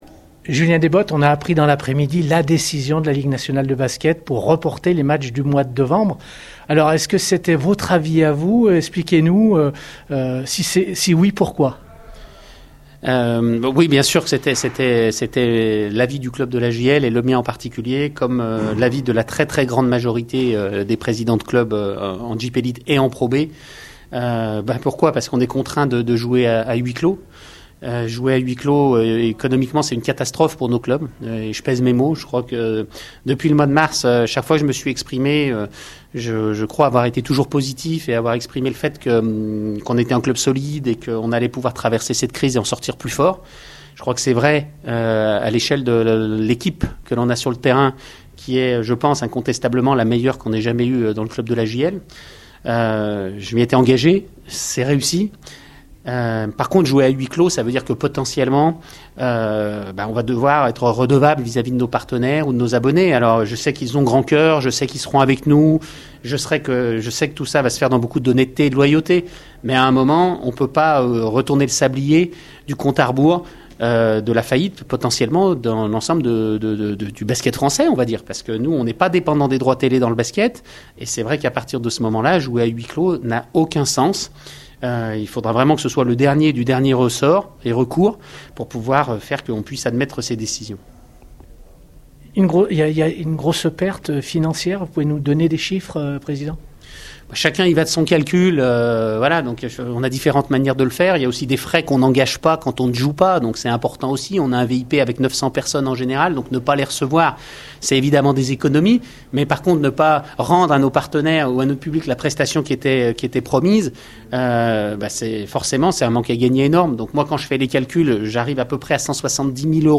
pour Radio Scoop